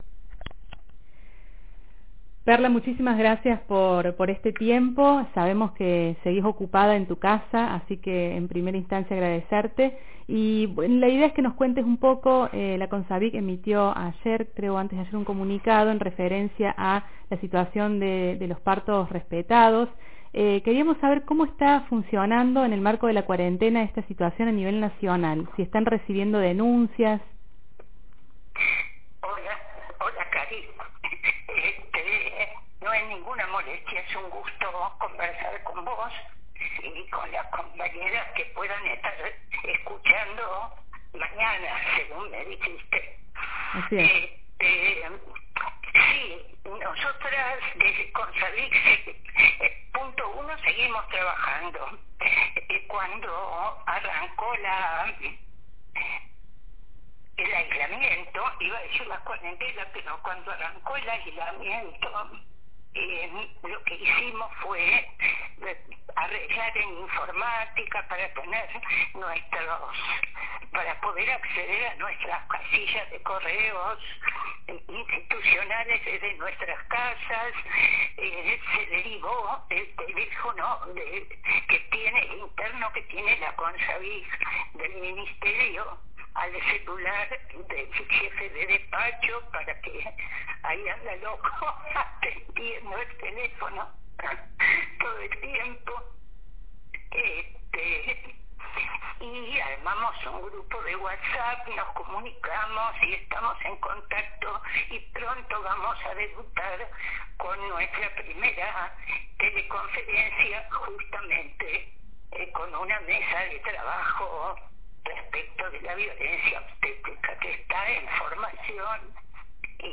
Compartimos la entrevista realizada con Perla Prigoshin, Titular de la Comisión Nacional Coordinadora de Acciones para la Elaboración de Sanciones de Violencia de Género (CONSAVIG), quien fue consultada por este medio en relación a la aplicación de la Ley de Parto Respetado a partir del decreto de aislamiento social, preventivo y obligatorio.